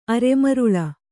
♪ aremaruḷa